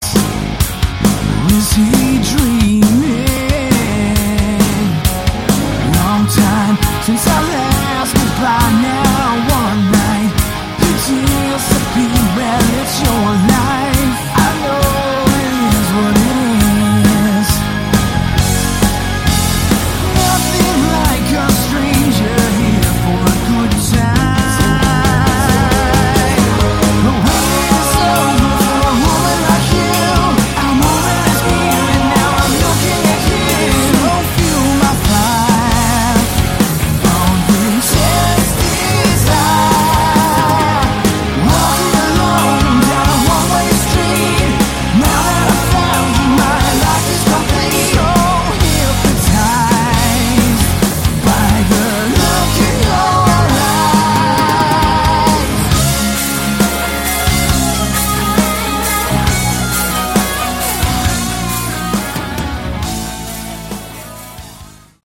Category: Melodic Rock
lead guitars, vocals
drums
keyboards, vocals
bass, vocals